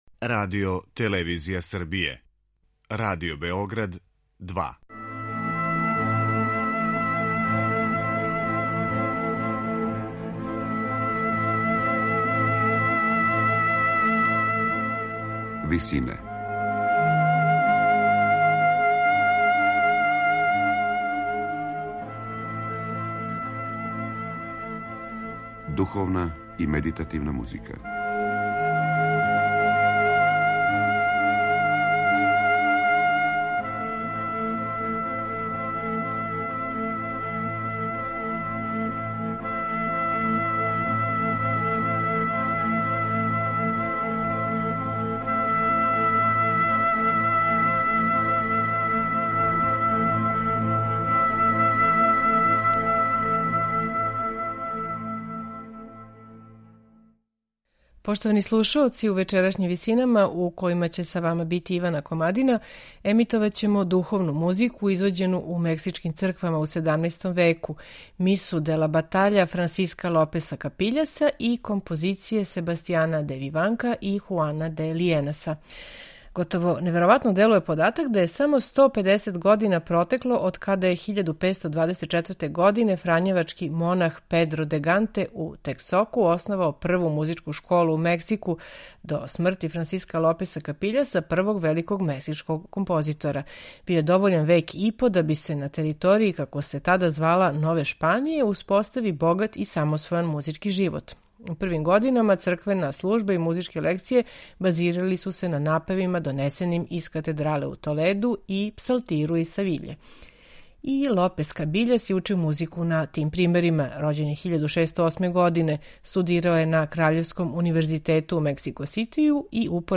Мексичка духовна музика
У вечерашњим Висинама емитоваћемо духовну музику извођену у мексичким црквама у 17. веку: мису „De la battaglia" првог великог мексичког композитора Франсиска Лопеса Капиљаса, као и композиције Себастијана де Виванка и Хуана де Лијенаса. Слушаћете их у интерпретацији коју је остварио вокални ансамбл „De profundis" из Монтевидеа